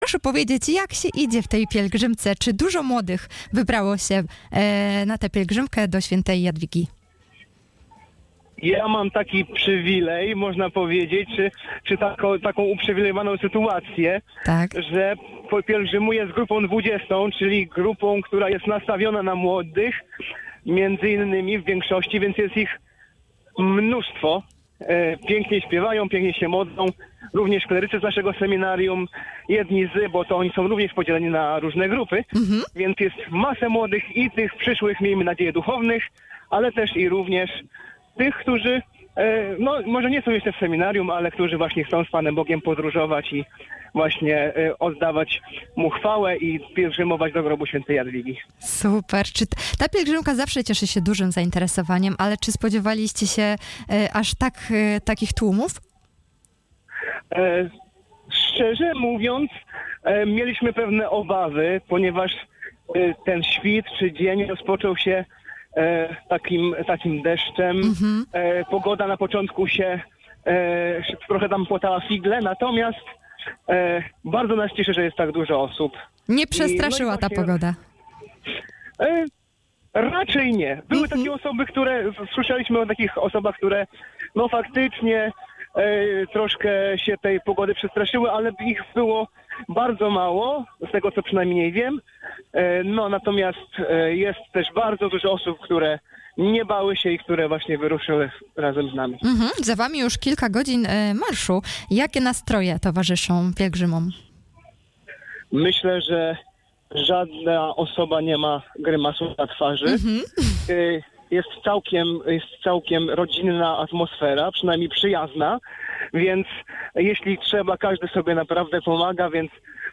rozmowa-na-antenie-PILEGRZYMKA1.mp3